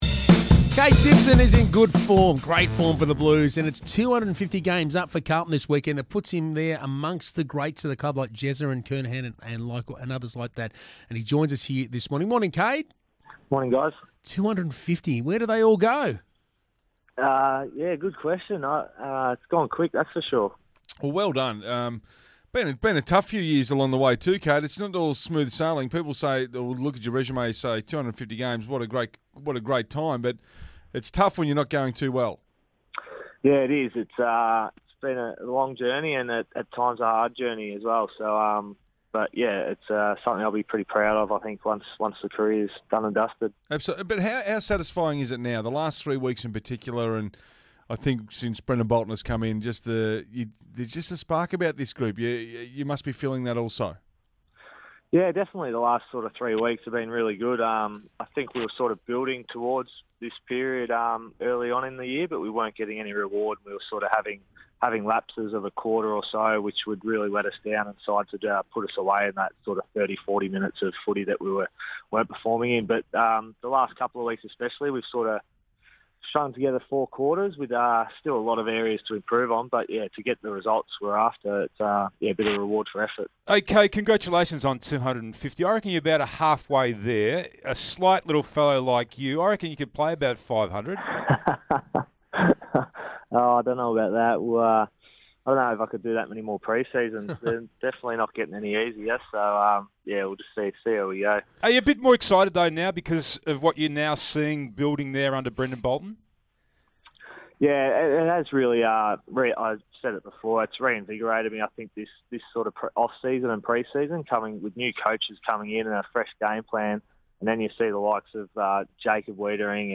Carlton vice-captain Kade Simpson speaks to SEN 1116 ahead of his 250th match.